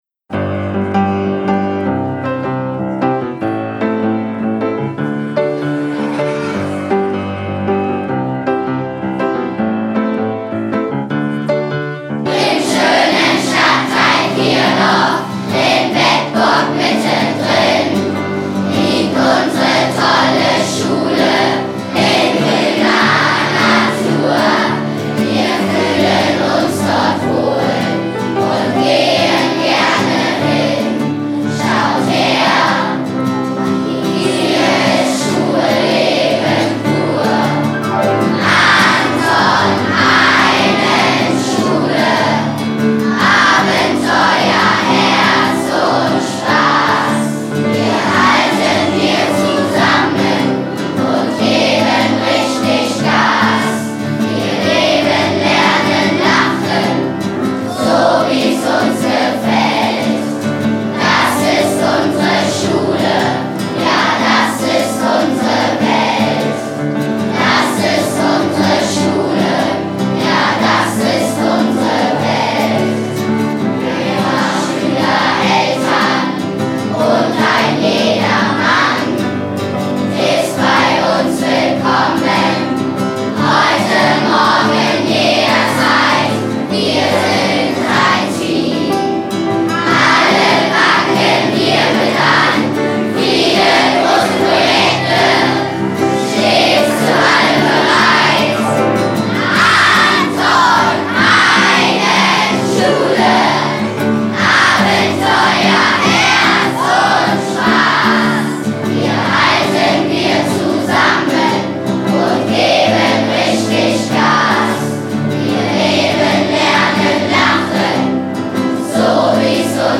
Die Anton-Heinen-Schule nimmt ihren Schulsong im CaPo auf.